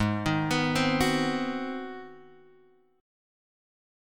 G# 7th Sharp 9th